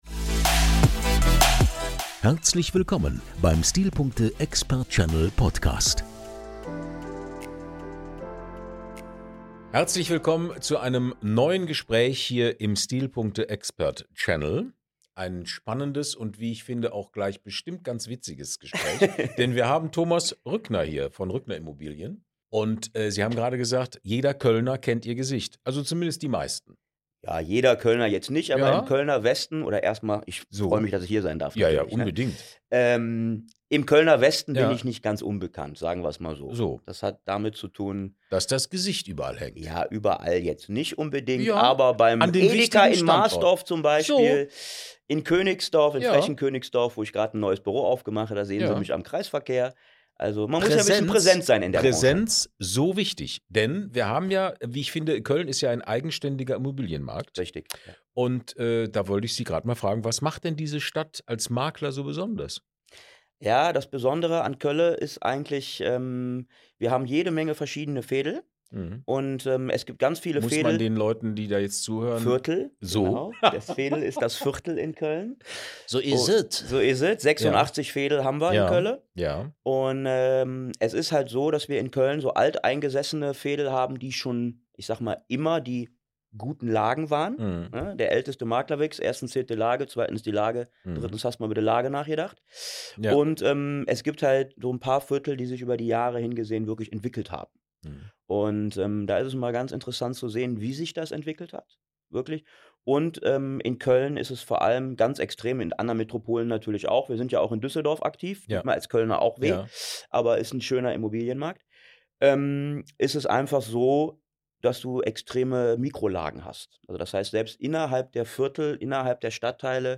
Ein Gespräch über Marktverständnis, Verantwortung und die neue Dynamik in einer Stadt, in der Lage allein nicht mehr alles entscheidet.